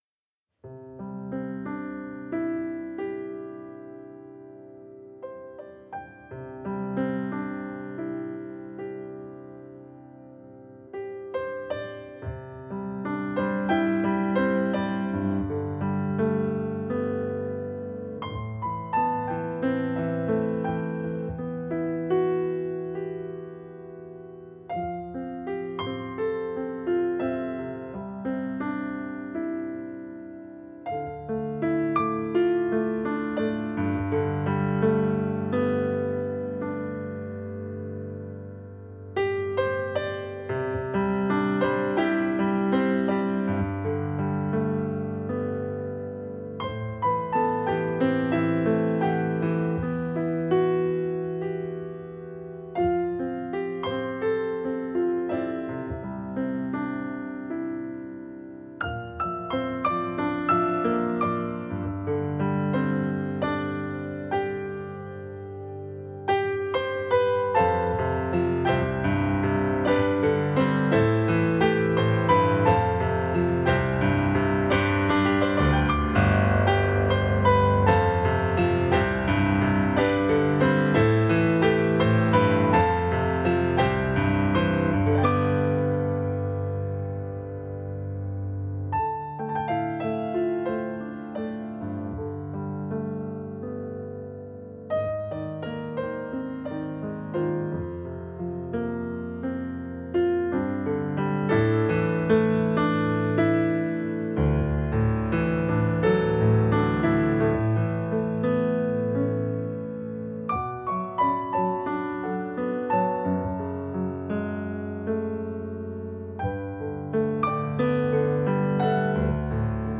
zeitlose, stimmungsvolle Kompositionen